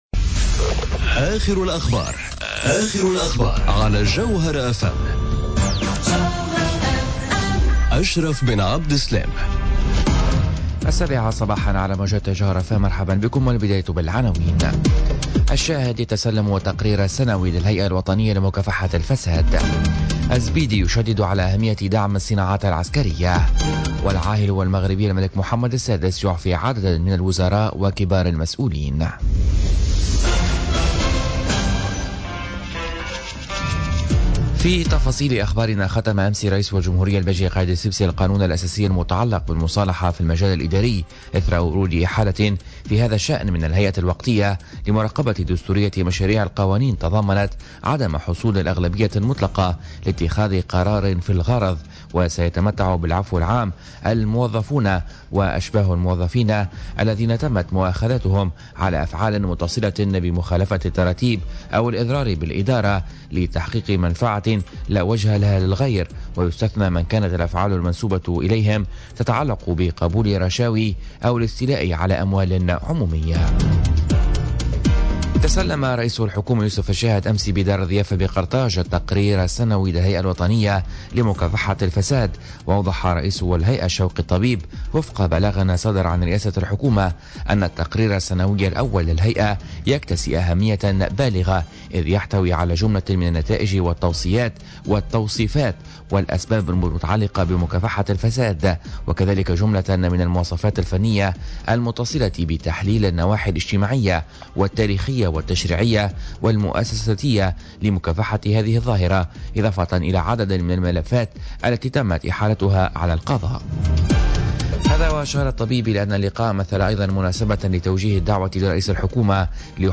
نشرة أخبار السابعة صباحا ليوم الاربعاء 25 أكتوبر 2017